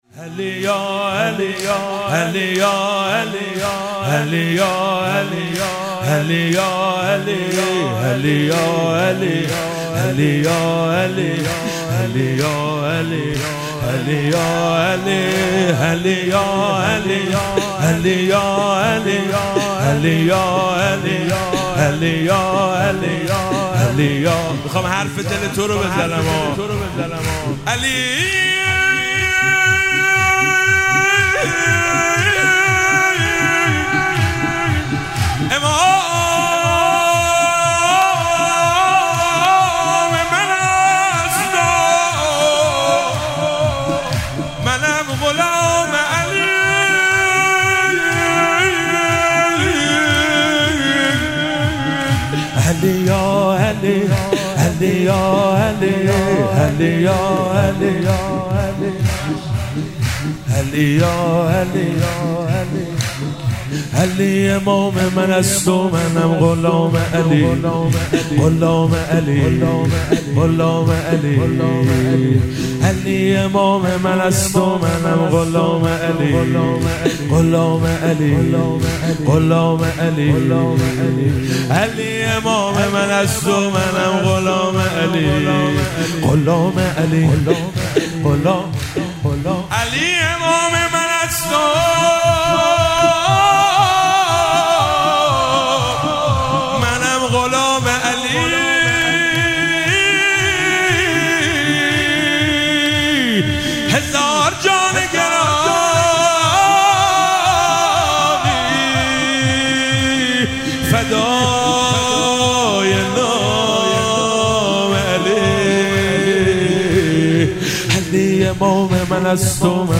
سرود- علی یاعلی، علی یاعلی
مراسم جشن شب سوم ویژه برنامه عید سعید غدیر خم 1444